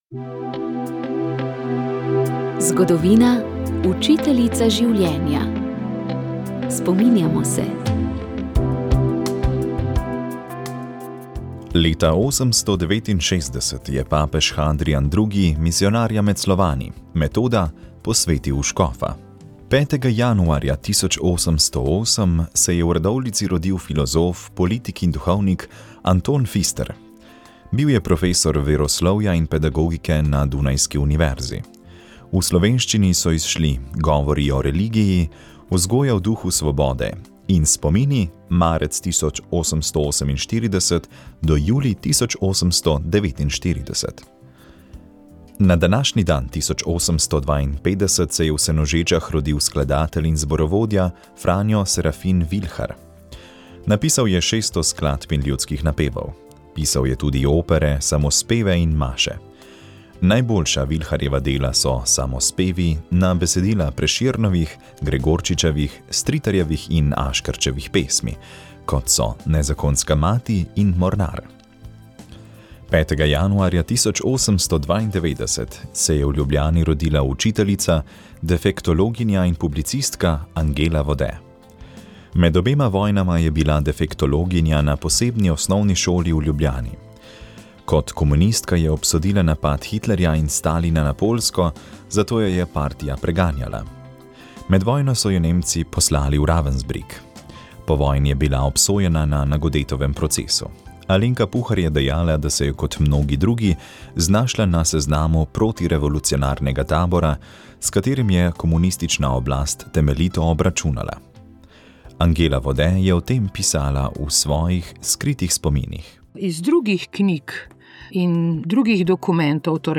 Pogovor